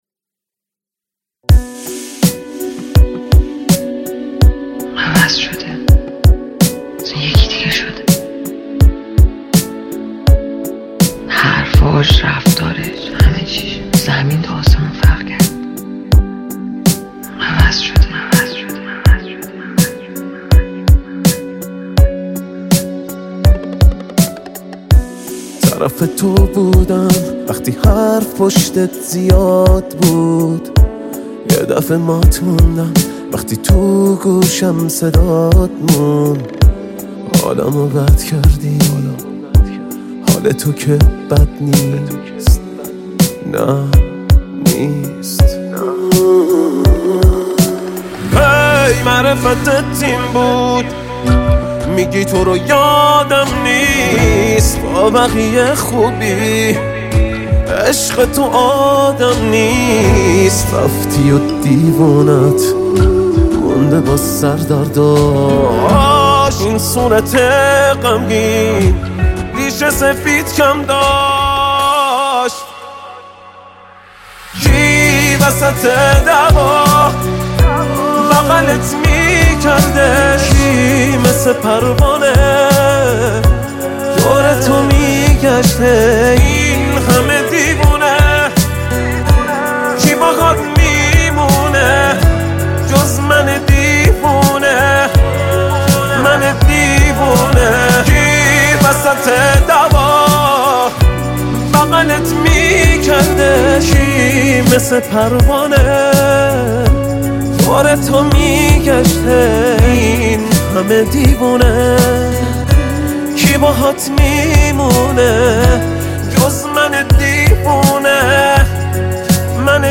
دانلود آهنگ شاد با کیفیت ۱۲۸ MP3 ۴ MB